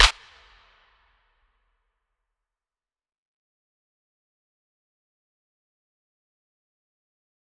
DMV3_Clap 11.wav